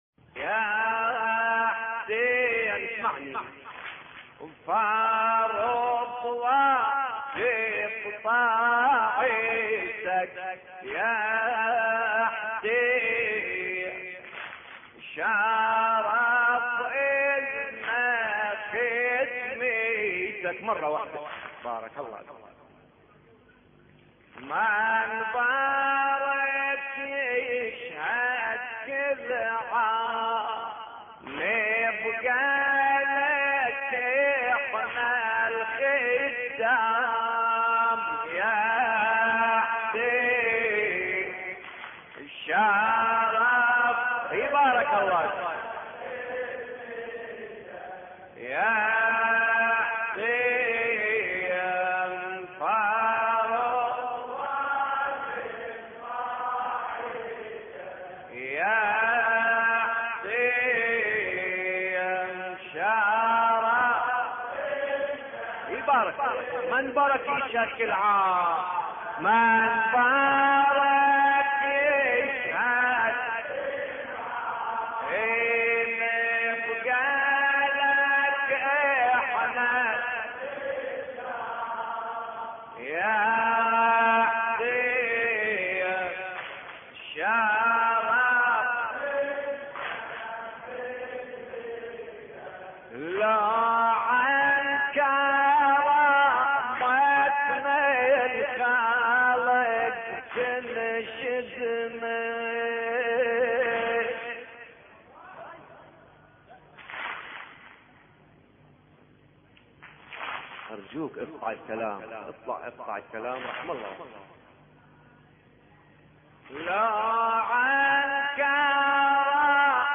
مراثي الامام الحسين (ع)